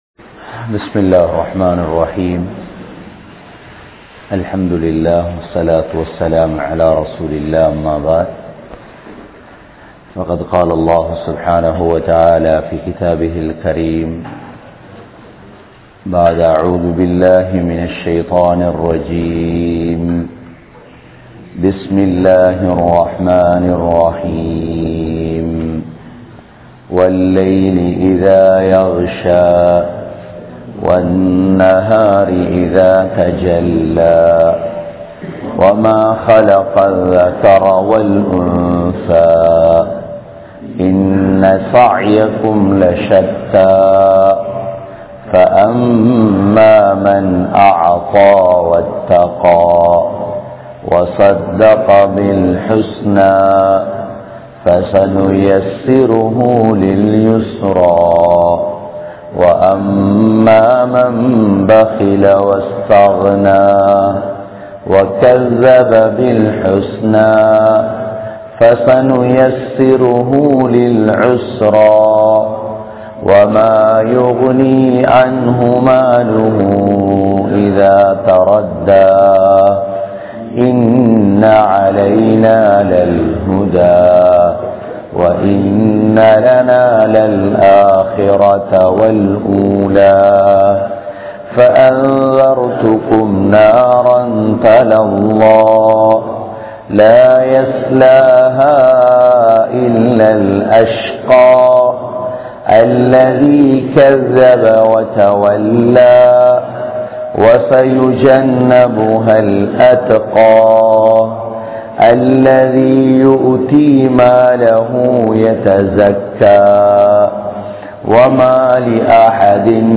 Aluthgama, Dharga Town, Meera Masjith(Therupalli)